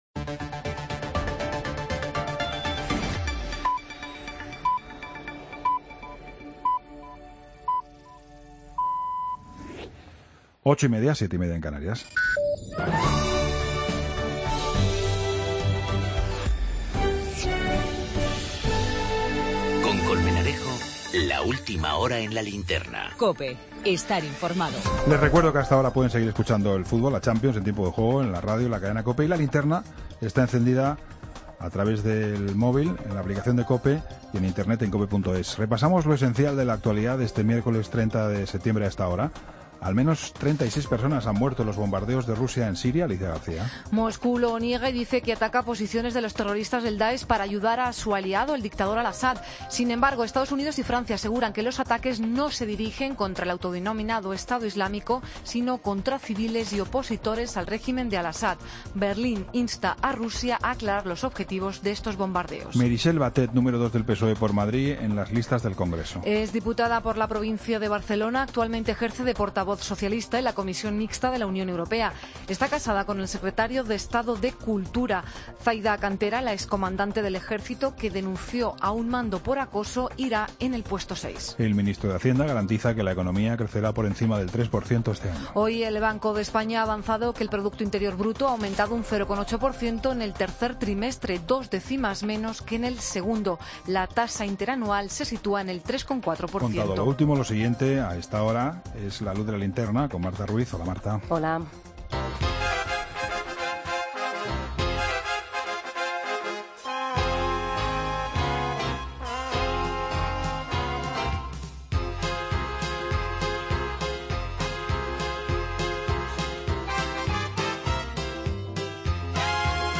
Entrevista a Paco Ureña, corrida benéfica.